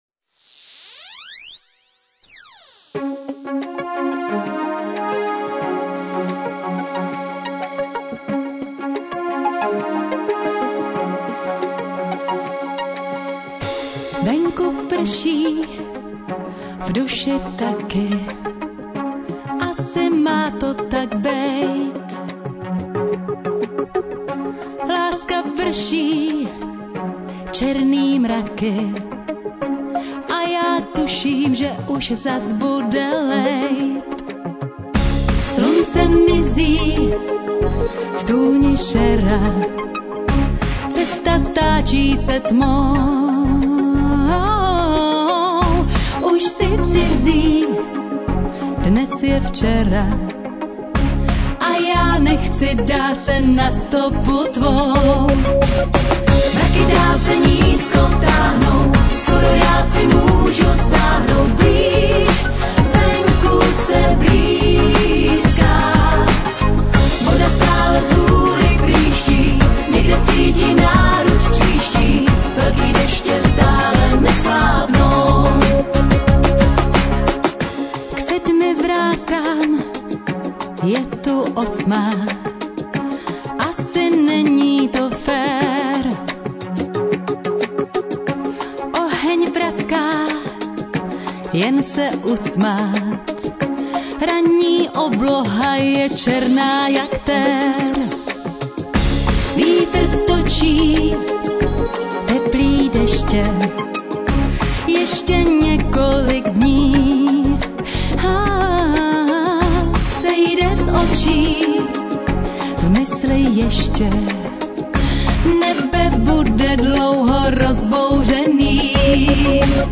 Nahráno v: Studiích Drama a studio Svengali